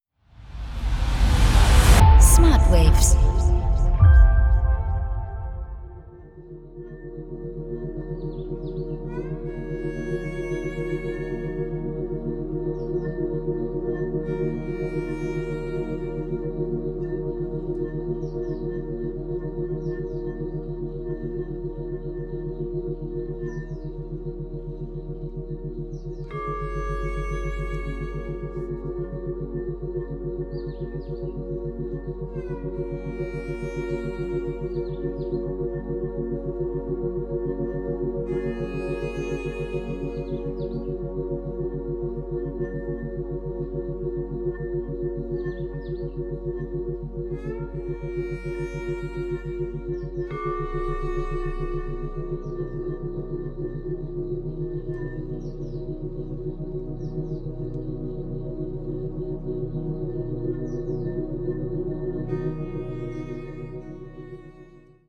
tiefe und entspannende Hintergrundmusik
• Methode: Binaurale Beats
• Frequenz: 5,5-7 Hertz